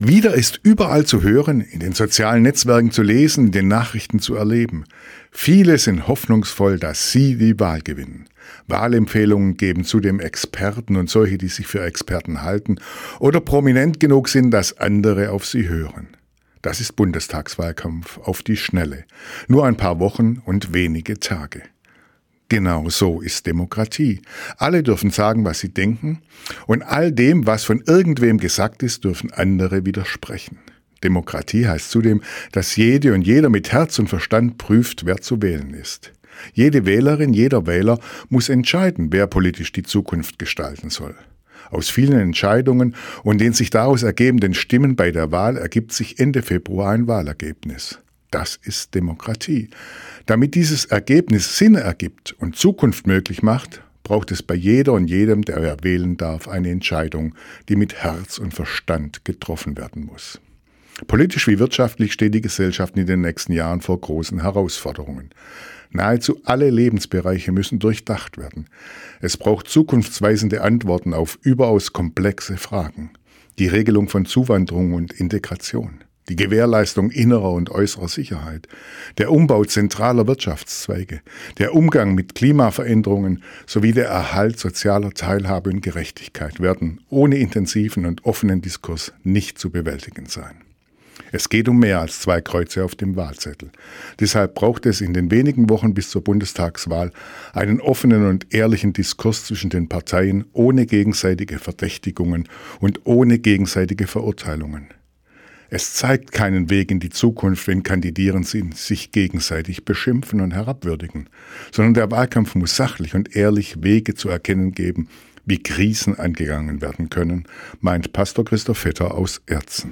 Radioandacht vom 14. Januar